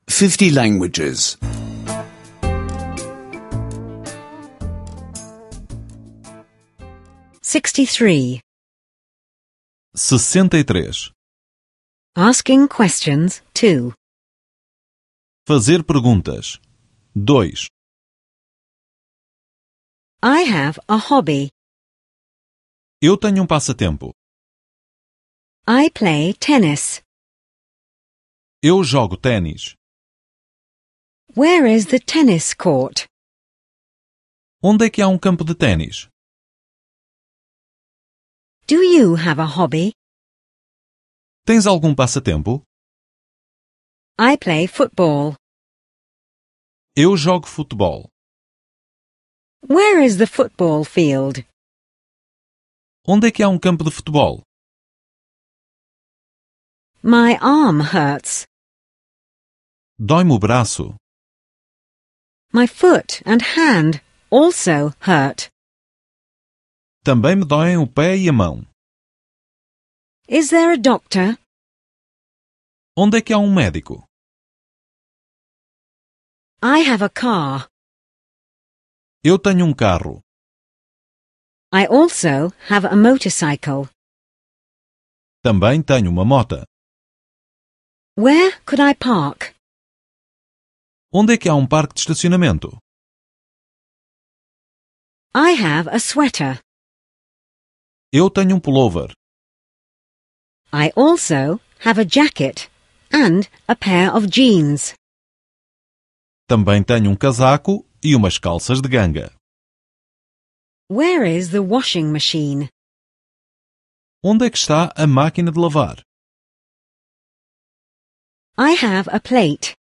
Here you will find all parts of Portuguese language audio tutorials.